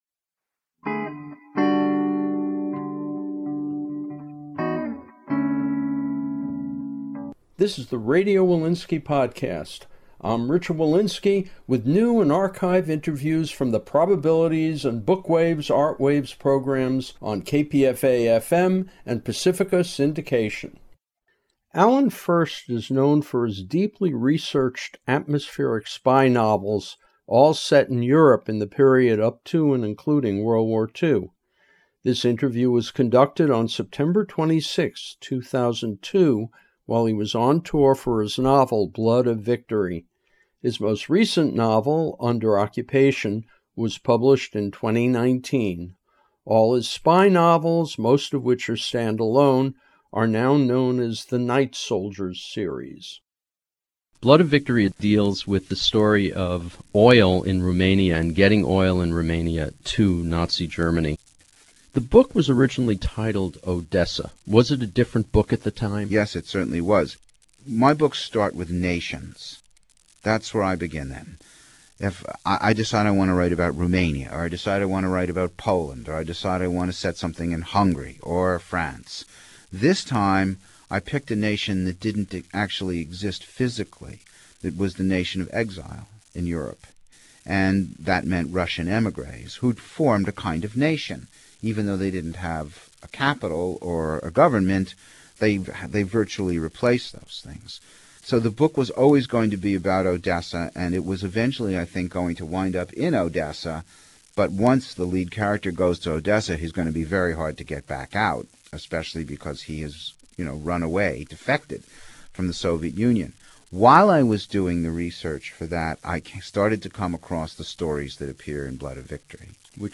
Interviews focused on books with side forays into film